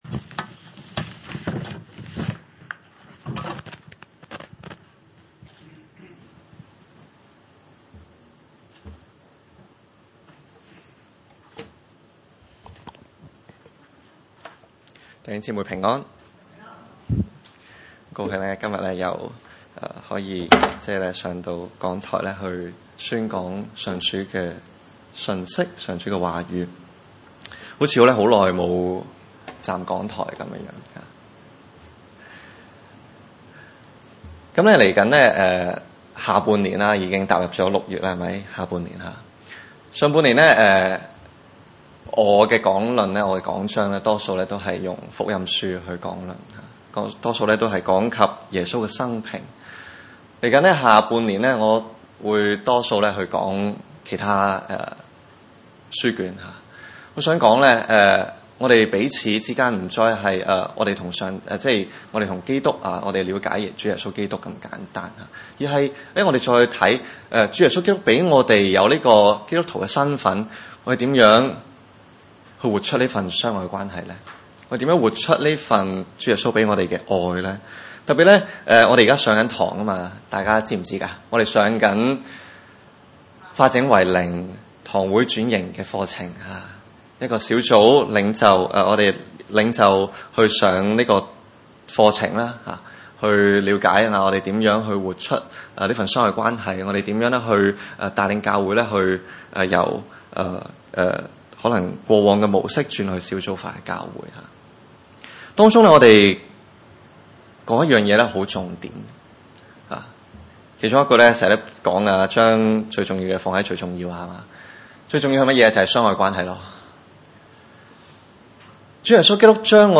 腓利門書1:1-25 崇拜類別: 主日午堂崇拜 1.